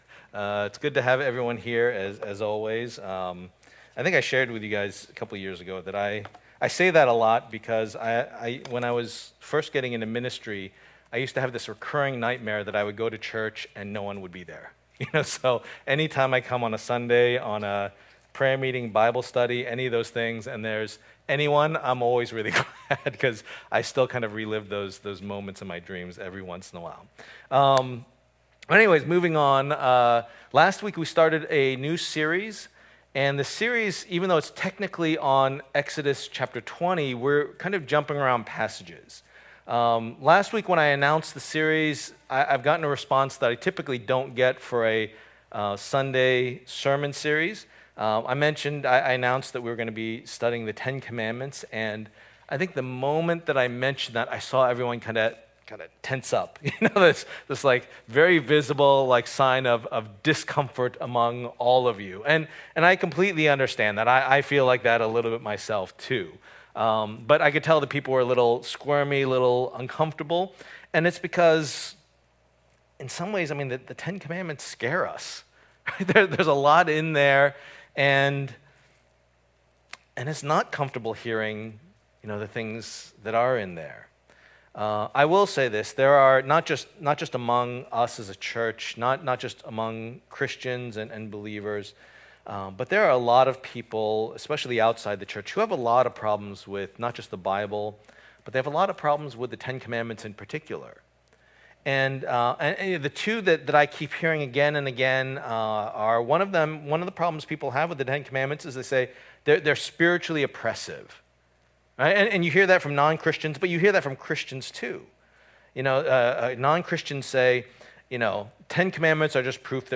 Series: The Ten Commandments Service Type: Lord's Day